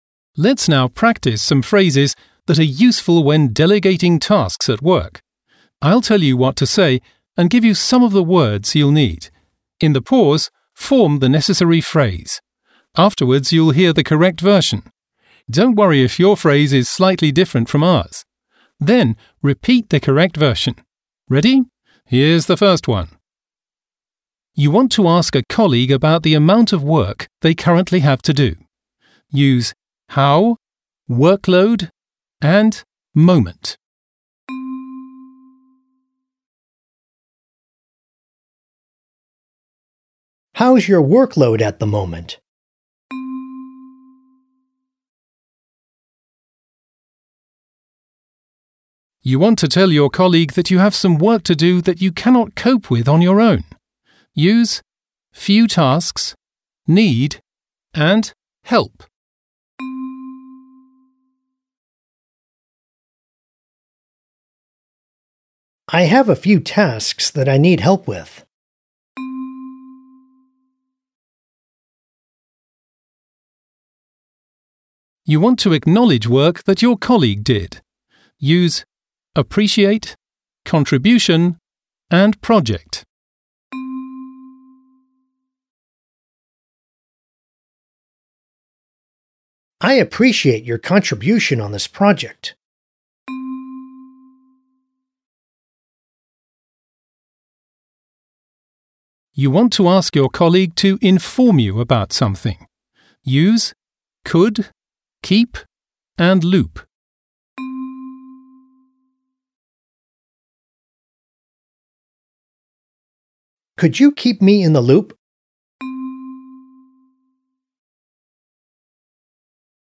Audio-Übung